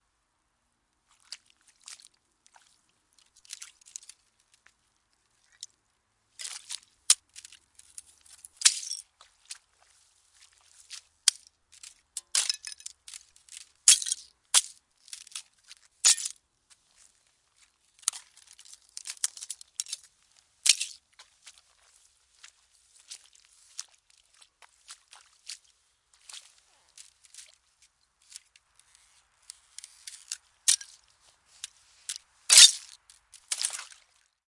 河流的波浪
描述：这是水的飞溅。
Tag: 波浪 水花四溅